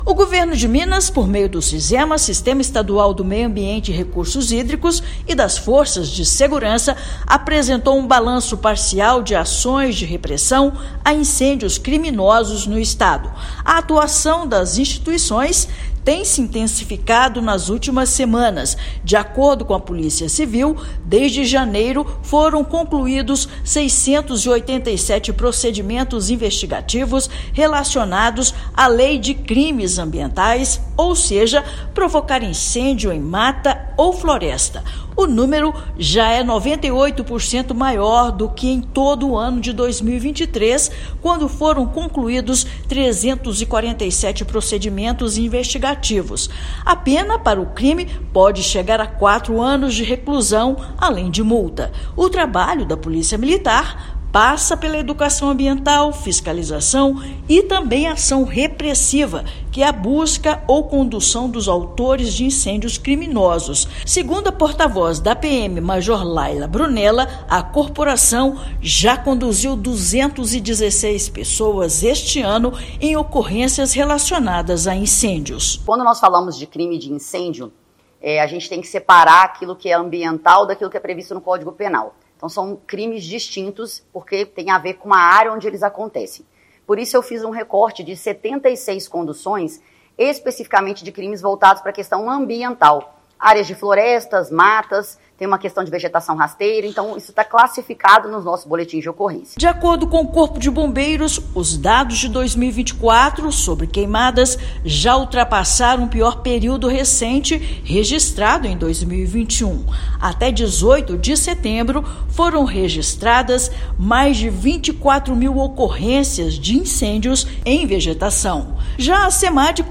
[RÁDIO] Governo de Minas intensifica procedimentos investigativos sobre incêndios em matas e florestas
Polícia Civil finalizou 687 apurações estratégicas, como autos de prisão em flagrante e diligências preliminares relativos somente ao Art 41 da Lei de Crimes Ambientais; Polícia Militar conduziu 216 pessoas, e multas aplicadas no estado somam R$ 10 milhões. Ouça matéria de rádio.